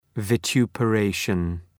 {vaı,tu:pə’reıʃən}
vituperation.mp3